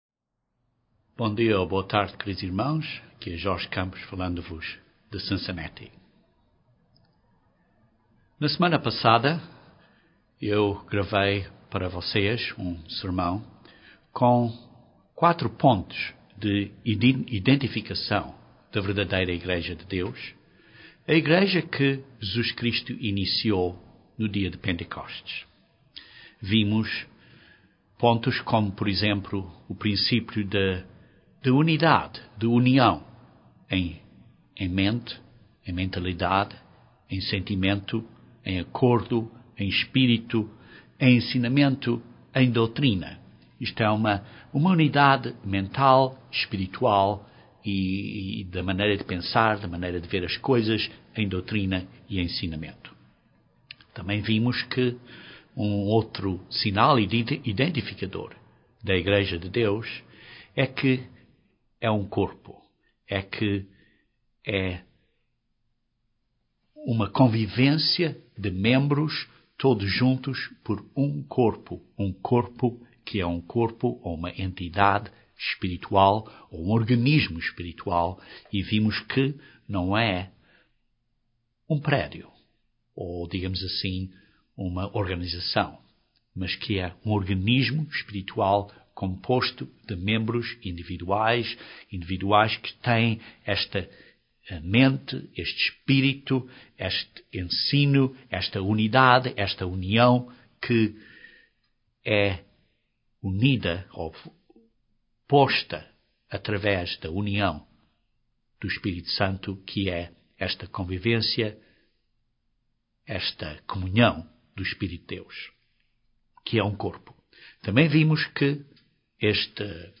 Uma das partes de identificação da verdadeira Igreja de Deus é a nossa própria responsibilidade de como nos conduzimos e do que fazemos coletivamente. Este sermão descreve analiza estes pontos.